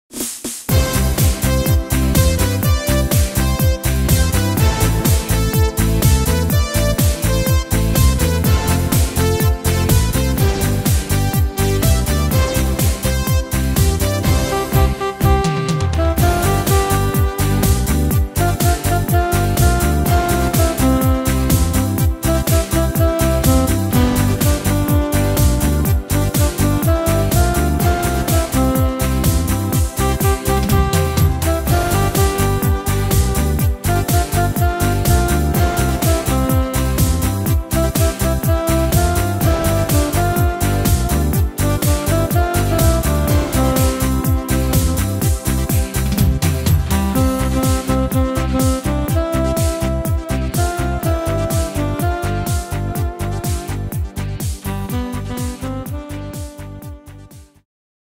Tempo: 124 / Tonart: C-Dur